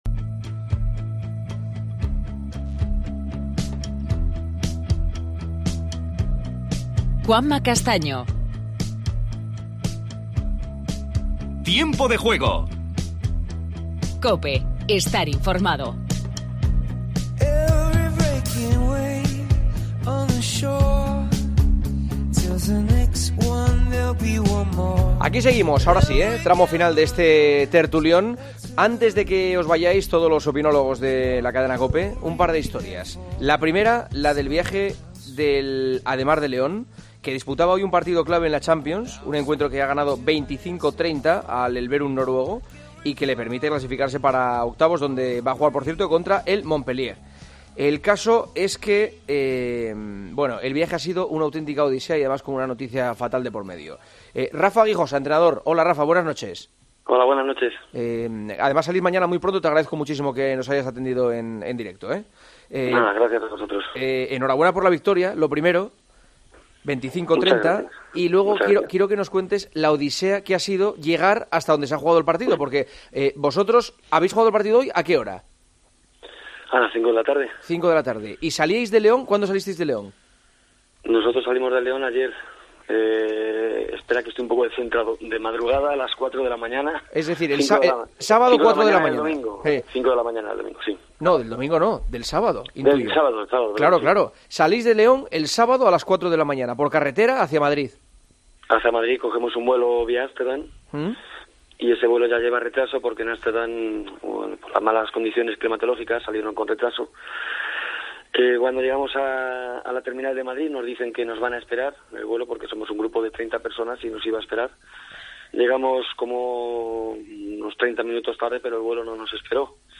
AUDIO: Continuamos con el tertulión.
Entrevistas a Rafa Guijosa, entrenador del Ademar de León; y Patxi Salinas, entrenador del Burgos.